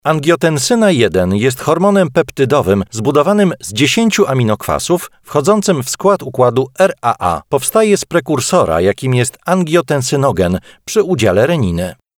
Male 30-50 lat
Sounds excellent as a narrator, in telecom systems and e-learning courses.
E-learning